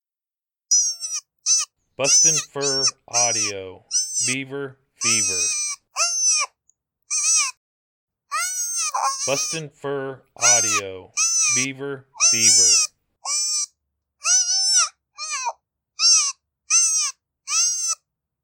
Baby Beaver in distress, excellent change up sound to use when Rabbits or Birds aren’t cutting it.